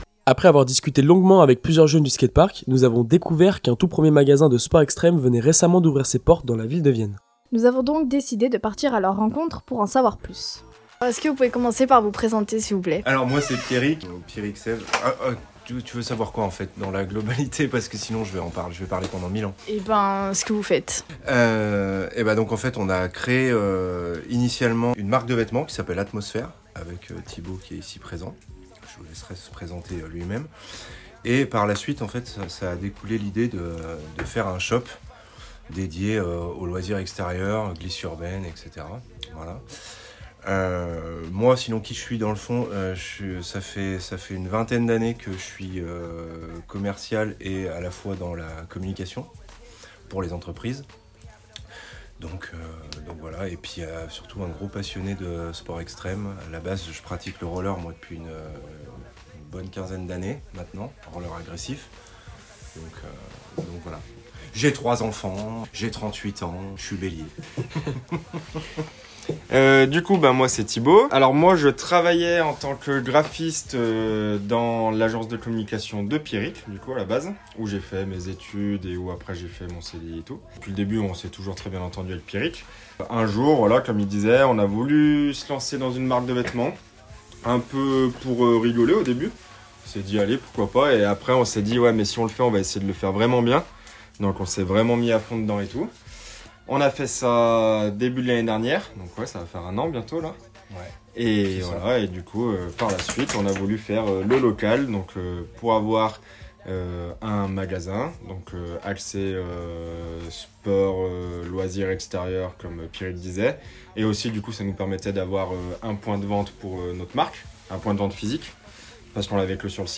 À : Vienne